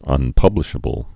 (ŭn-pŭblĭ-shə-bəl)